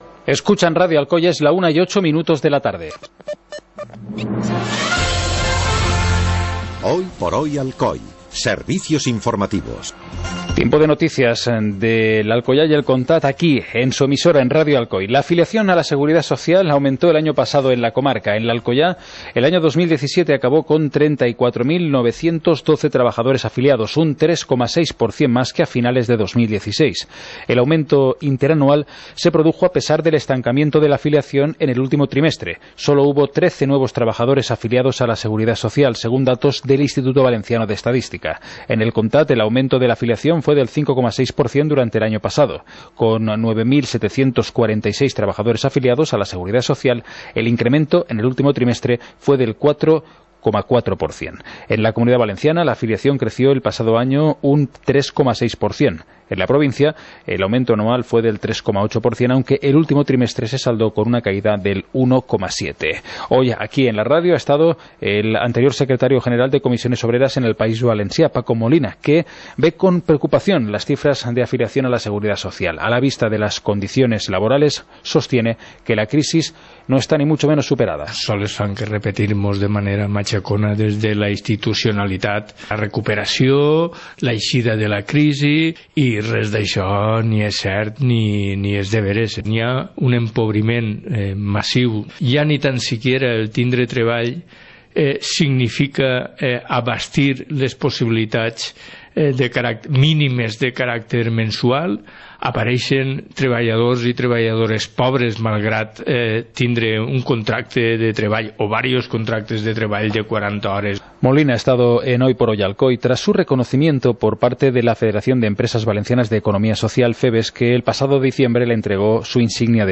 Informativo comarcal - lunes, 22 de enero de 2018